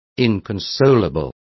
Complete with pronunciation of the translation of inconsolable.